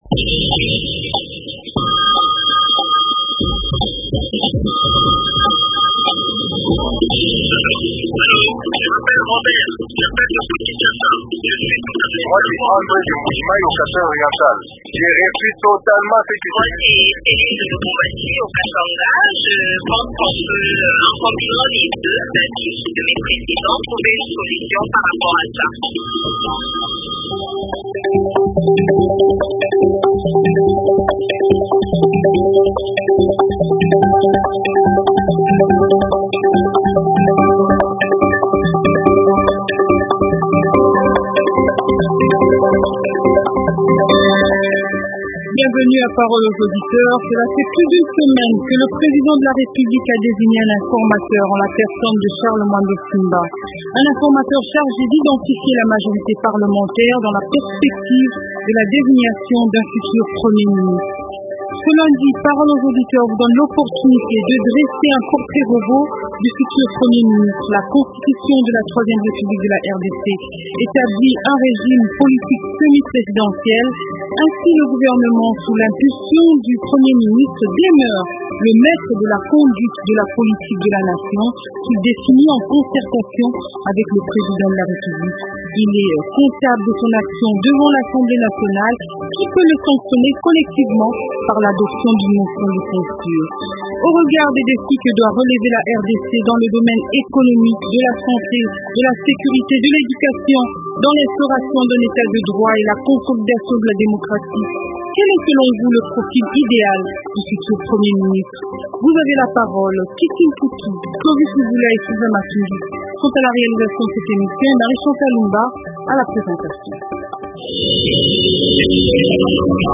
Ce lundi Parole aux auditeurs vous donne l’opportunité de dresser le profl du futur Premier ministre.